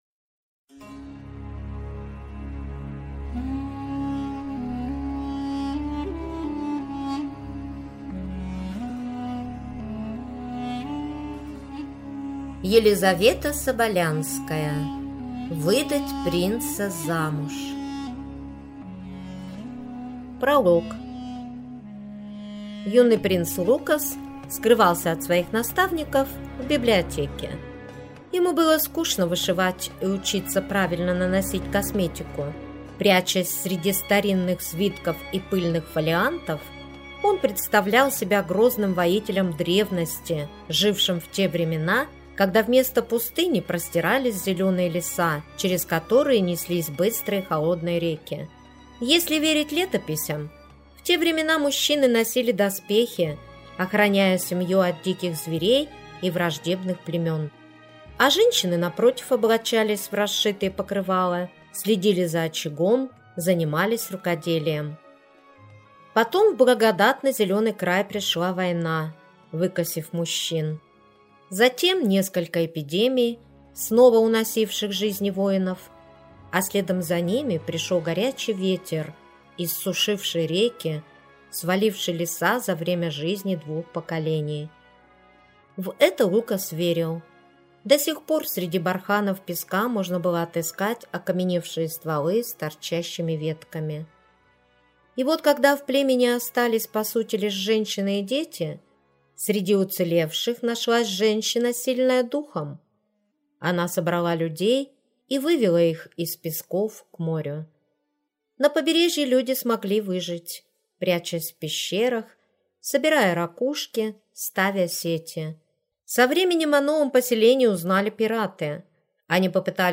Аудиокнига Выдать принца замуж | Библиотека аудиокниг